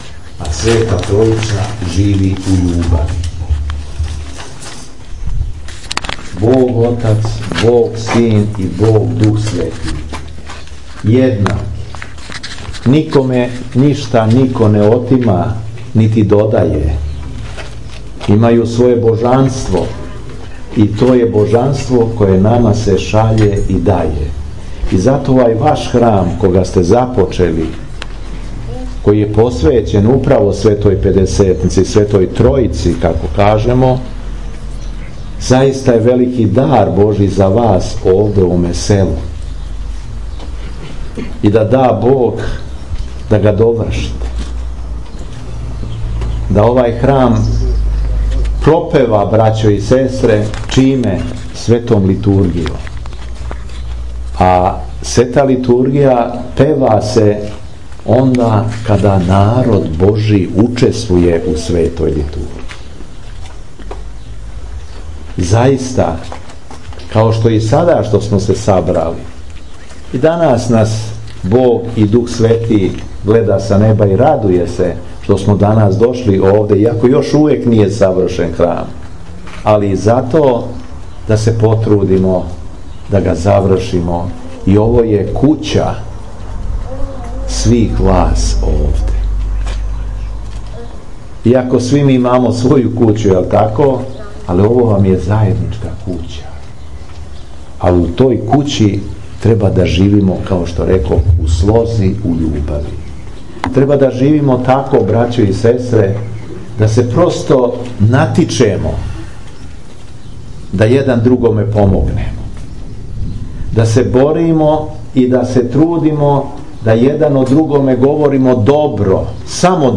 У беседи упућеној окупљеним верницима овог лепог шумадијског села, владика Јован је поручио да је пред Богом велико дело то што су започели изградњу храма и да ће га са Божијом помоћи и завршити, али да исто тако треба да се труде и да изграђују себе као храм Божији.
Беседа Епископа шумадијског Г. Јована